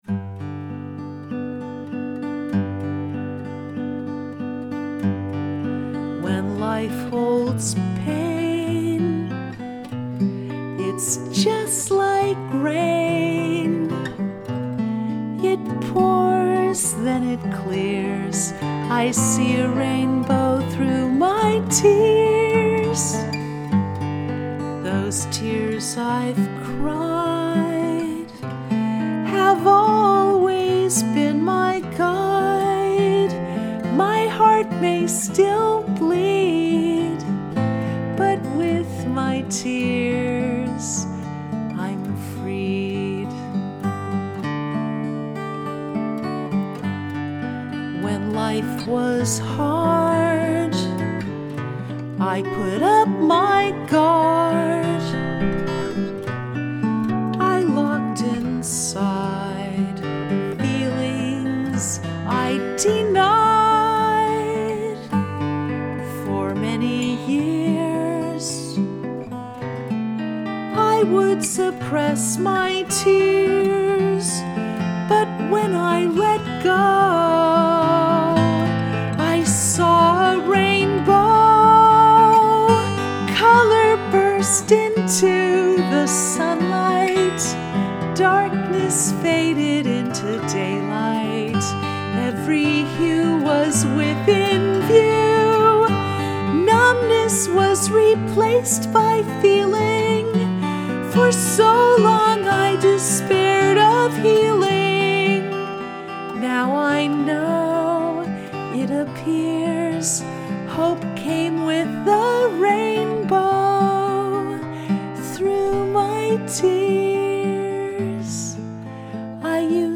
Rainbow Through My Tears Home Recording 4-5-18
The song truly became joyful when we added four-part harmony to the chorus.
rainbow-through-my-tears-acoustic-mix-22-1.mp3